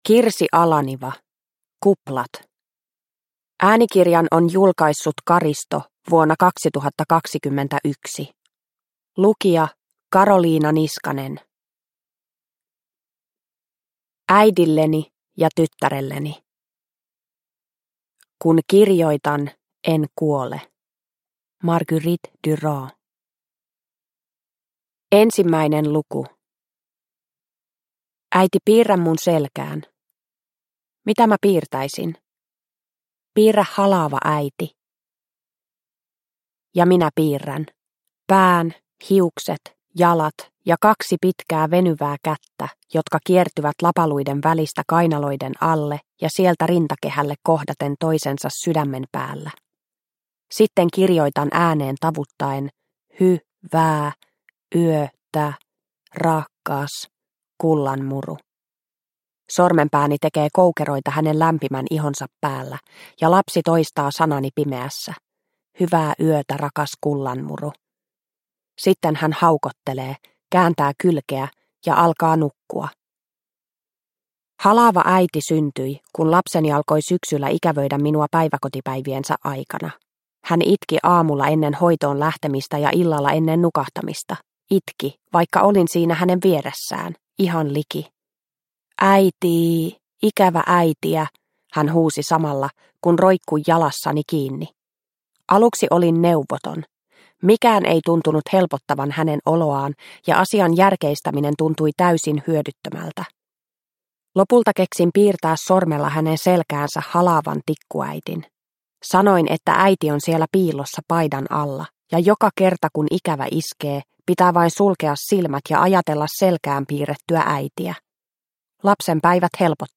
Kuplat – Ljudbok – Laddas ner